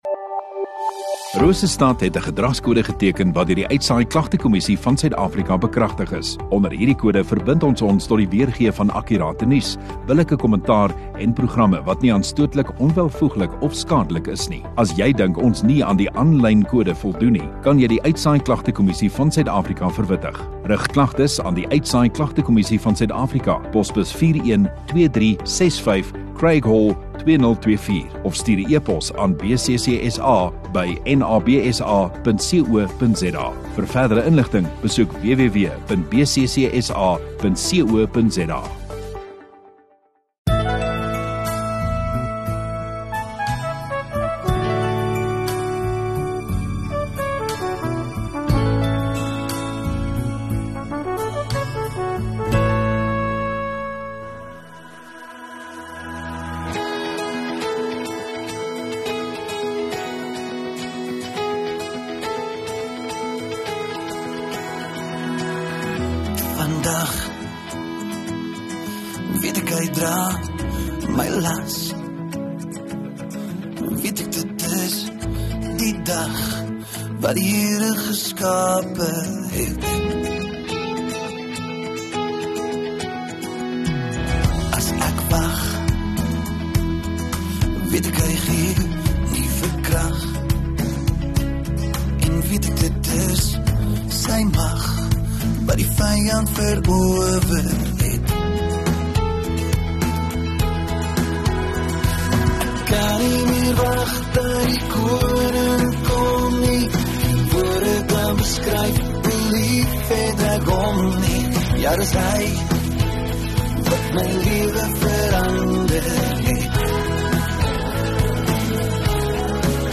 5 Oct Saterdag Oggenddiens